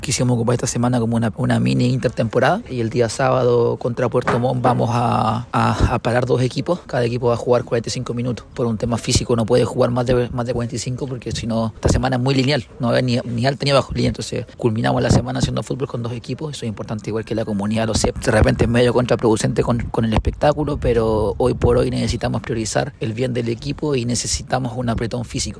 Así lo expresó el DT, a Radio Sago.